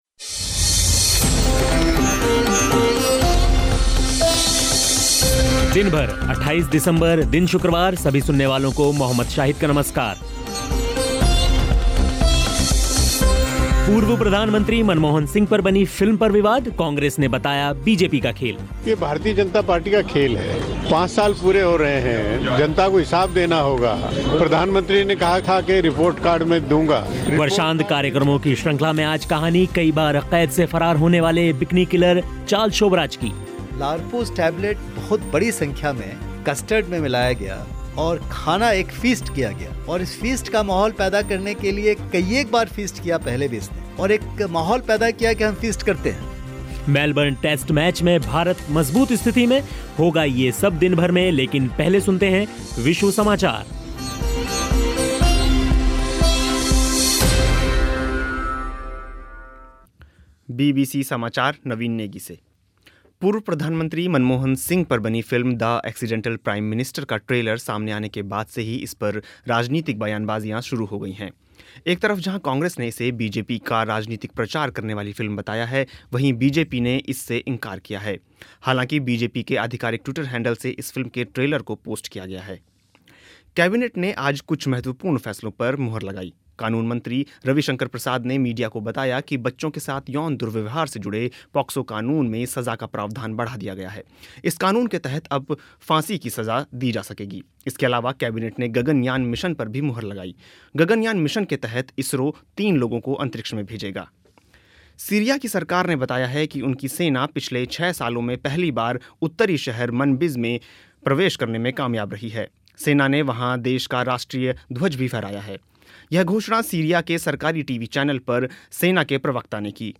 मेलबर्न टेस्ट मैच में भारत मज़बूत स्थिति में लेकिन पहले सुनिए विश्व समाचार.